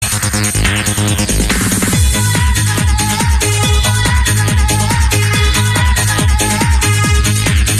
he added a sample of female vocals.